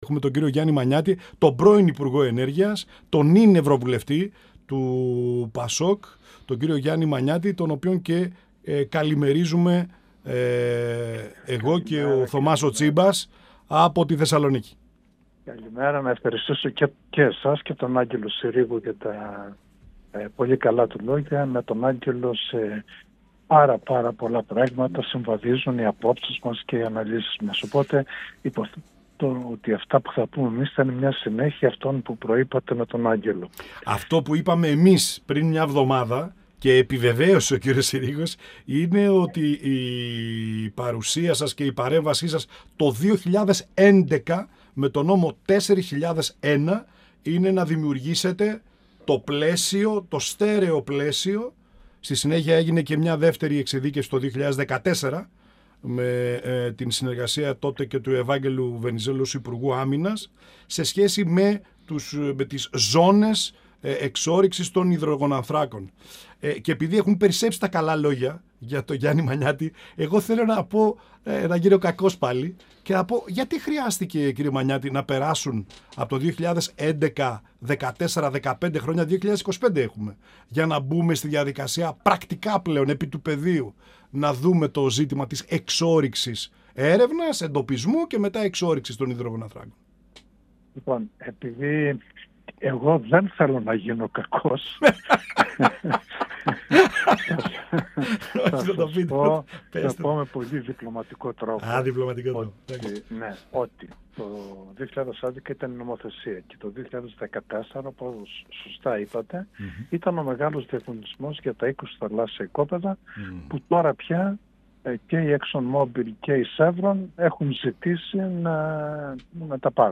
Στη σημαντική τομή του νόμου 4001 του 2011,που εισηγήθηκε ο ίδιος δημιουργώντας το πλαίσιο των εξορύξεων των υδρογονανθράκων αναφέρθηκε ο Ευρωβουλευτής του ΠΑΣΟΚ Γιάννης Μανιάτης, μιλώντας στην εκπομπή «Πανόραμα Επικαιρότητας» του 102FM της ΕΡΤ3.
Συνεντεύξεις